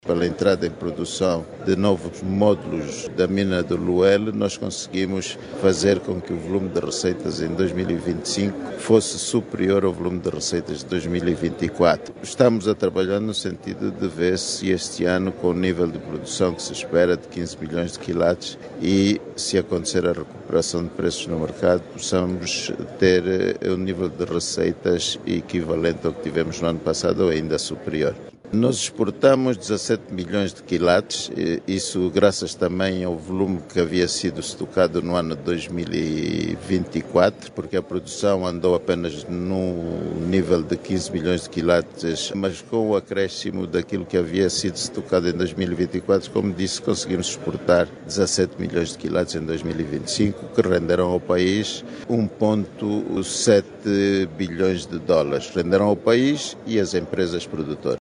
Angola espera comercializar quinze milhões de kilates de diamantes neste ano para superar a venda feita nos anos passados. O dado foi avançado ontem, quarta-feira(11) pelo PCA da SODIAM, Bravo da Rosa, durante o fórum sobre o sector mineiro que decorre na cidade do cabo na África do sul.